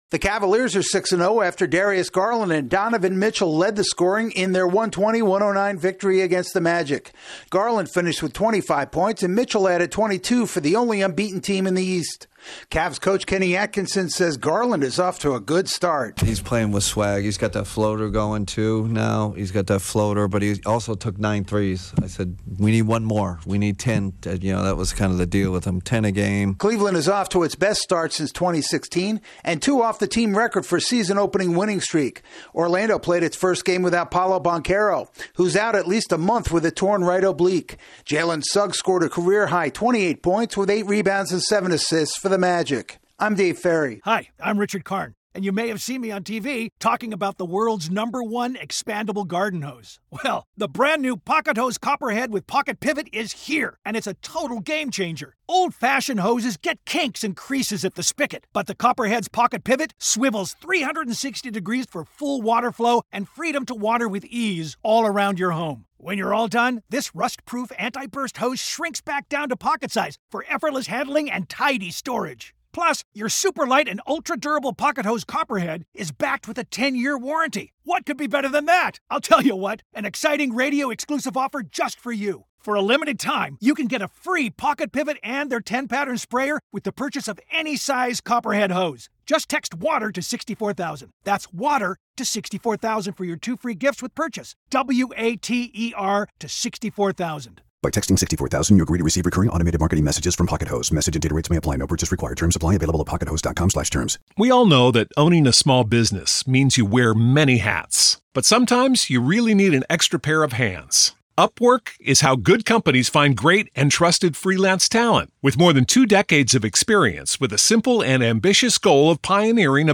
The Cavaliers continue their perfect start. AP correspondent